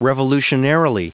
Prononciation du mot revolutionarily en anglais (fichier audio)
Prononciation du mot : revolutionarily